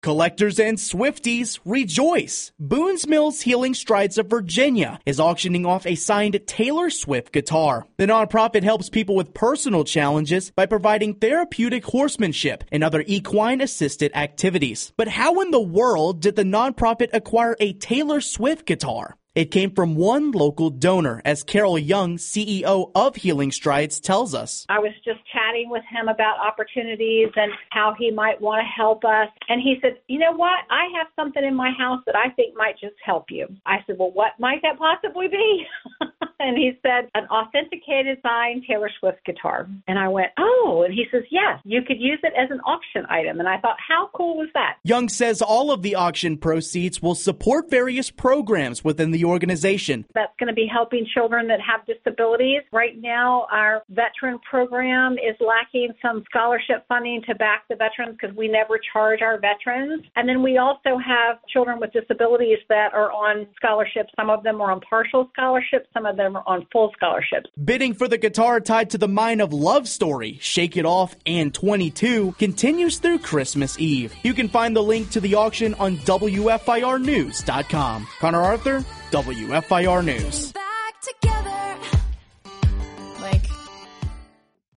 12-8-Swift-Guitar-AM-Wrap-1-WEB.mp3